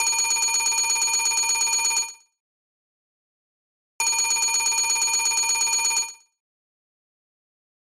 フリー効果音：電話
懐かしの電話のベルをどうぞ！黒電話のシーンや昭和などのレトロ紹介系の動画にぴったり！
telephone.mp3